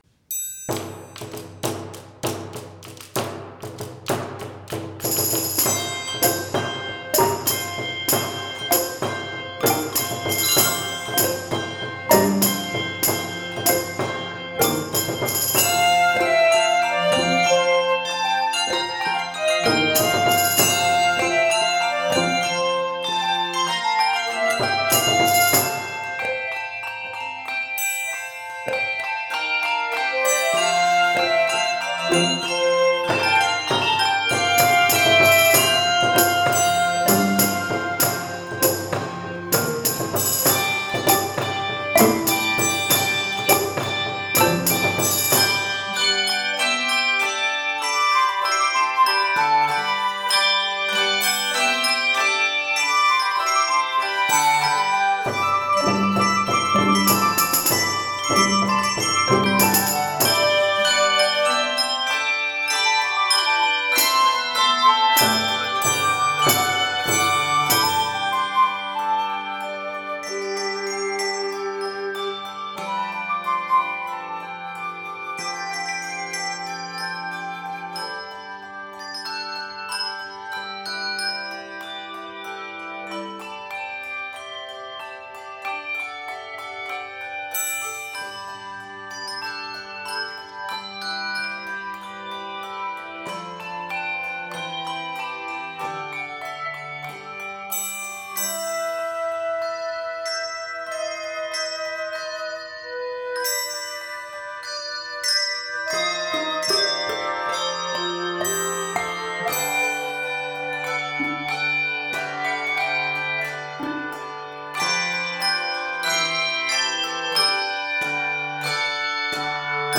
flute and percussion
Keys of F Major and G Major.